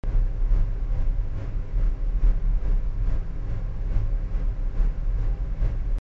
ventilator.mp3